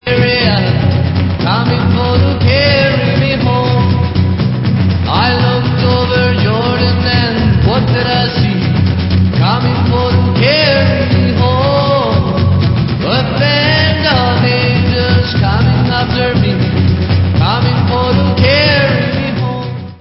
sledovat novinky v oddělení Pop/Instrumental